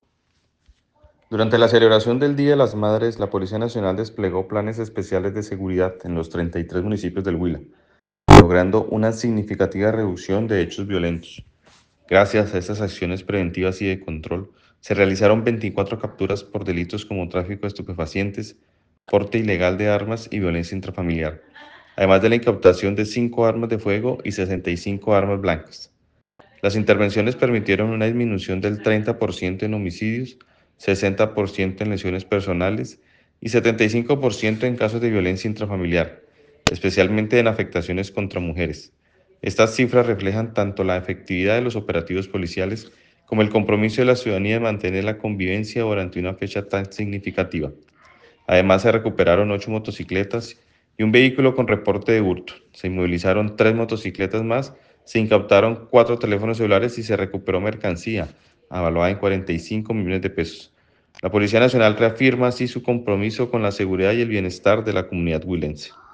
Audio:Coronel Carlos Eduardo Téllez Betancourt, comandante del departamento de policía Huila